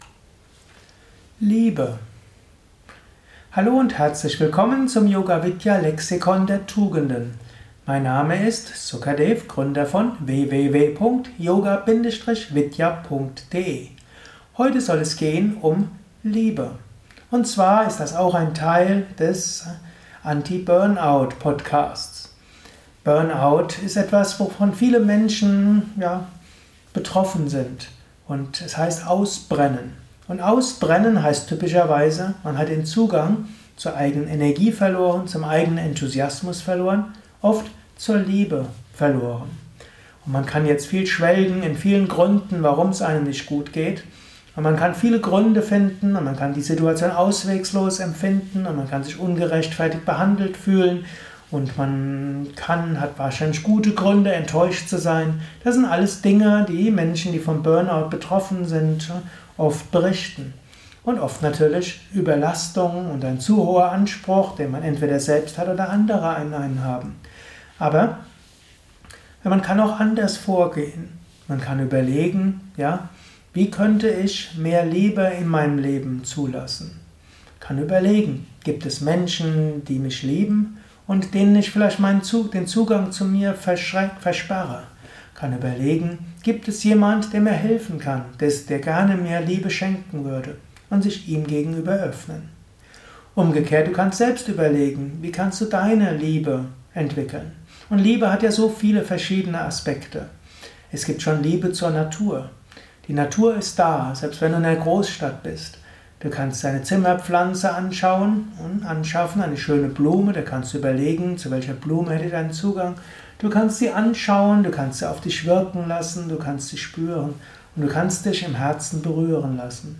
in diesem Kurzvortrag